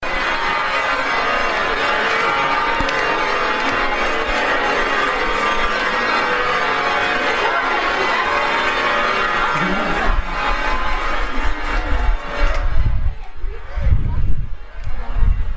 ambiance sonore près du campanile.